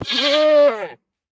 Minecraft Version Minecraft Version snapshot Latest Release | Latest Snapshot snapshot / assets / minecraft / sounds / mob / goat / death4.ogg Compare With Compare With Latest Release | Latest Snapshot
death4.ogg